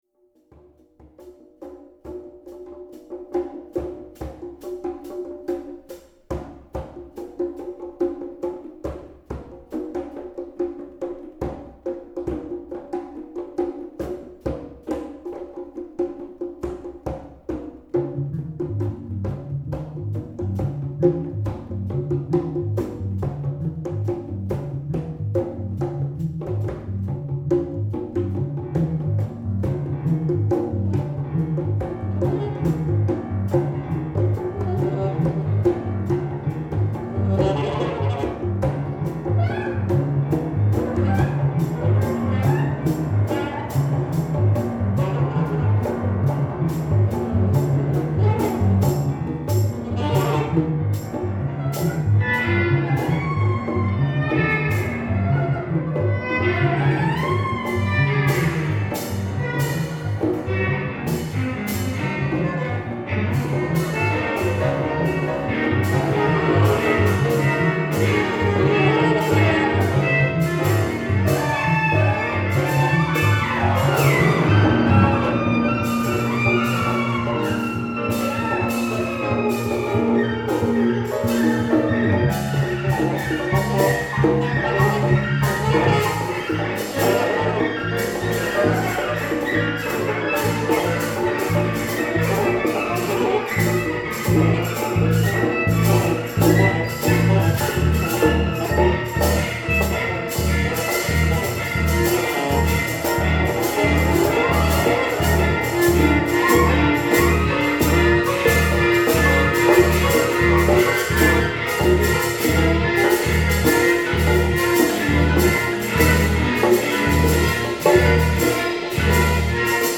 “The Magic Church of the Mythocracy” (excerpt) – for alto sax, electric violin, piano, electric bass, and percussion.
performed by Splank(bang) in Athens, OH. May 2011.